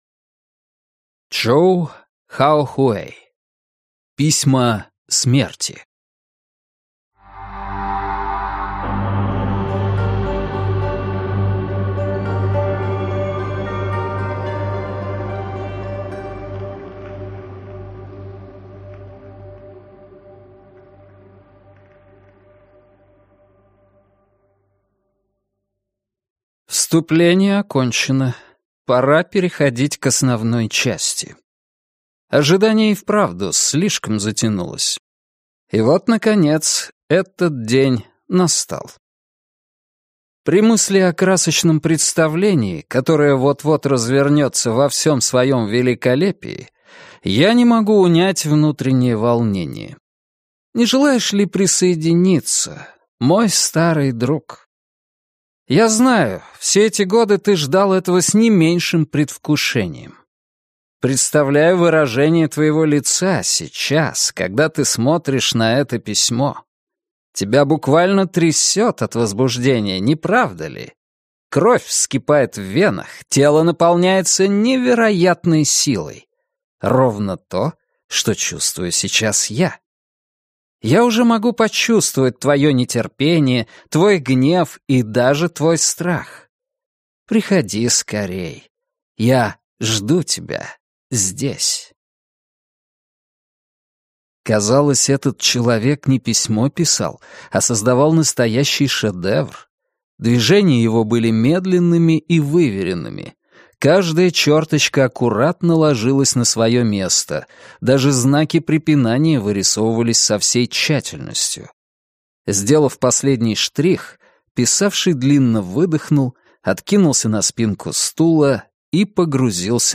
Аудиокнига Письма смерти | Библиотека аудиокниг